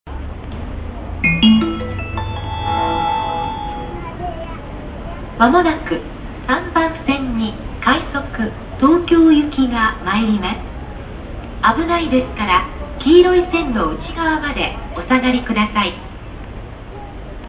接近放送
接近放送です。
これは現在の音声で、